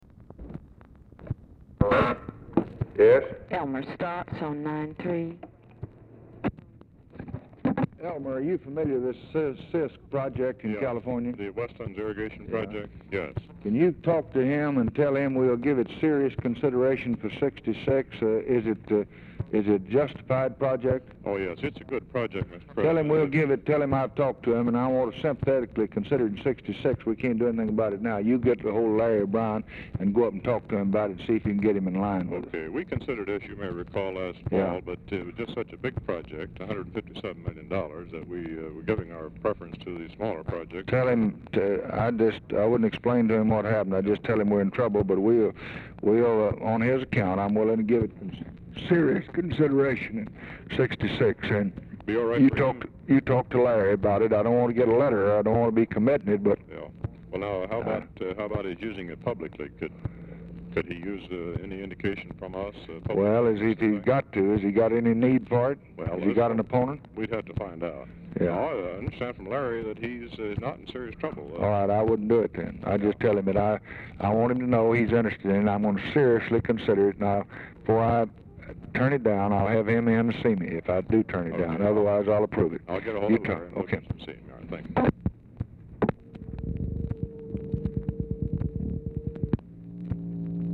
Telephone conversation # 3749, sound recording, LBJ and ELMER STAATS, 6/16/1964, 9:44AM
Format Dictation belt
Location Of Speaker 1 Oval Office or unknown location